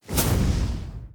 Fireball 2.wav